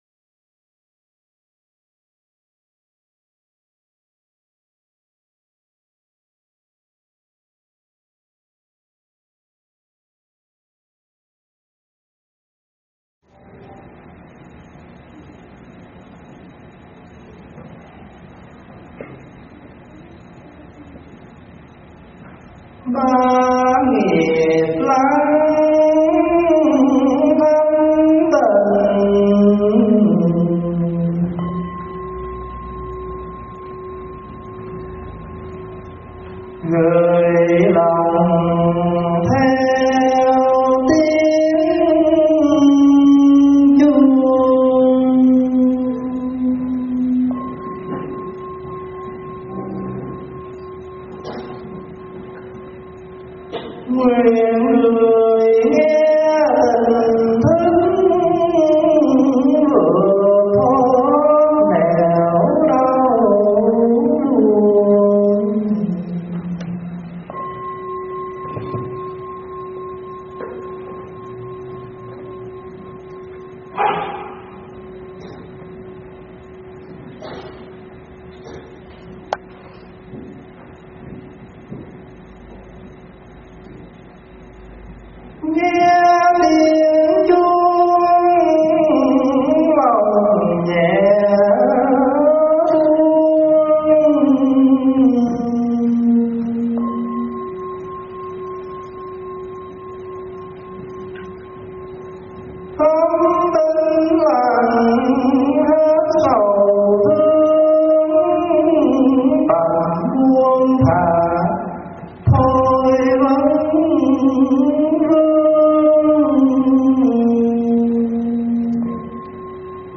Mp3 pháp thoại Ý Nghĩa Tu Bát Quan Trai
giảng trong chương trình Thọ Bát Quan Trai